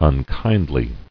[un·kind·ly]